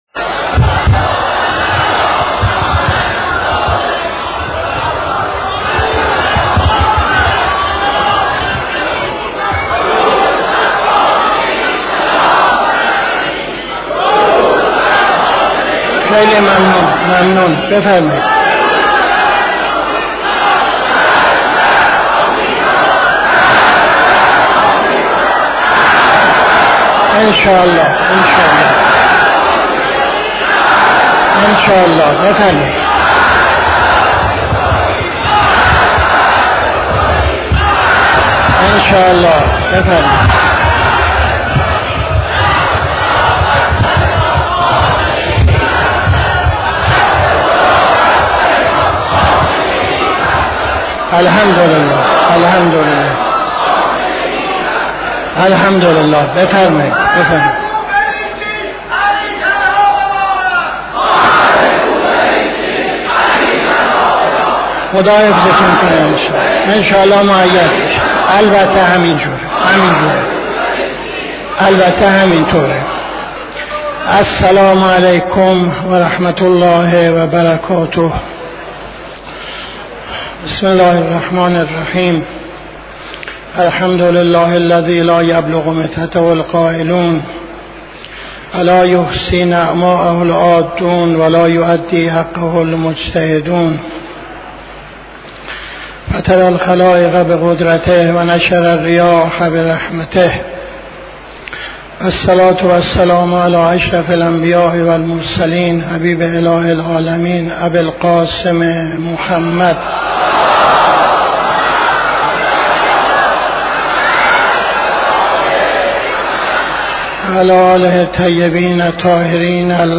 خطبه اول نماز جمعه 25-06-78